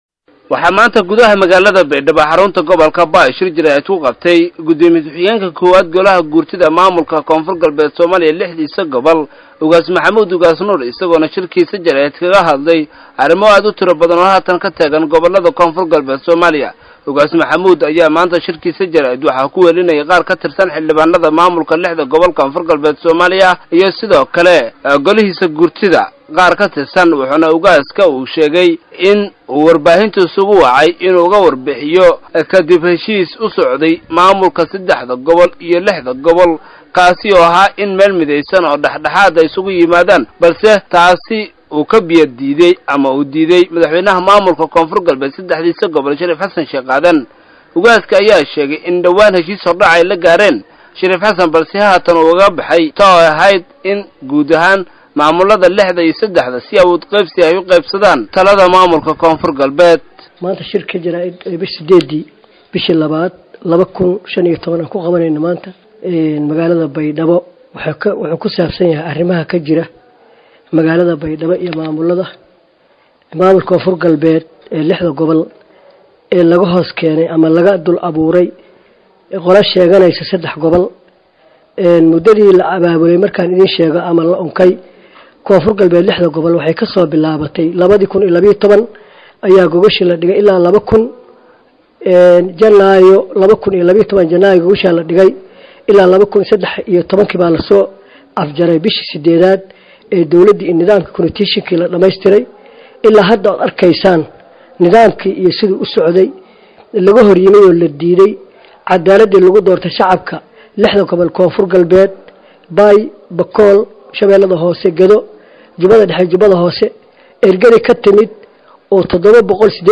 Ugaas Maxamed Ugaas Nuur Gudoomiye ku xigeenka Golaha Guurtida KoonfurGalbeed 6gobol oo shir Jaraaid ku qabtay magaalada Baydhabo ayaa waxa uu ku canbaareeyay Shariif Sakiin iyo Jaaliyadaha Dibada.